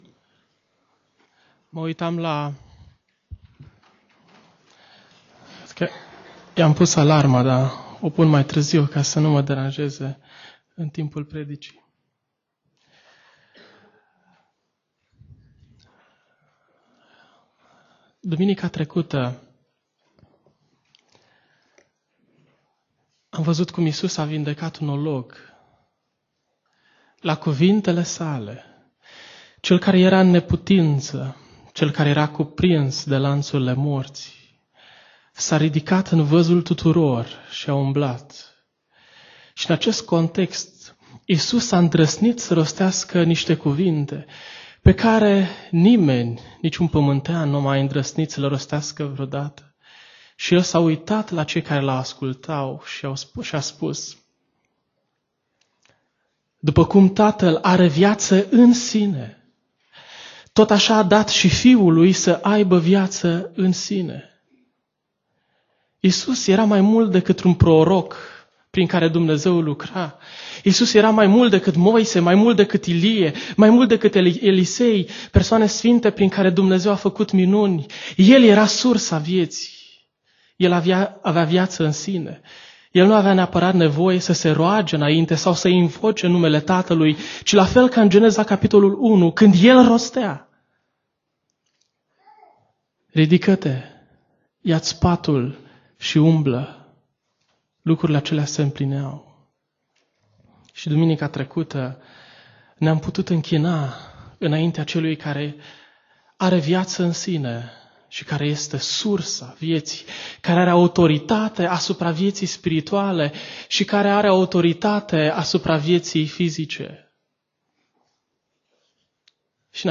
Predica Exegeza - Ev. dupa Ioan cap 6